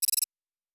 pgs/Assets/Audio/Sci-Fi Sounds/Interface/Data 16.wav at 7452e70b8c5ad2f7daae623e1a952eb18c9caab4